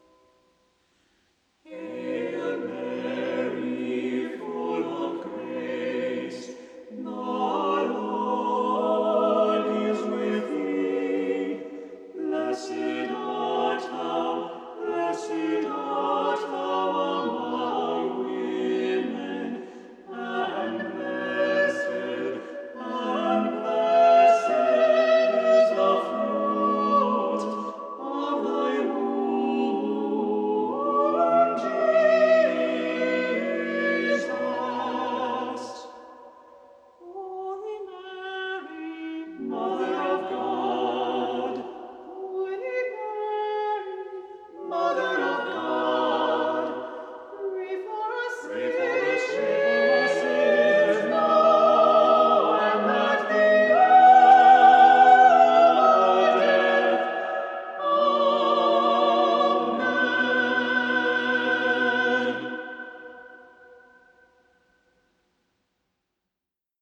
The organ has 62 stops and close to 3,200 pipes.
On October 3, 2022, we recorded the following hymns at the Basilica of Our Lady of Perpetual Help:
organ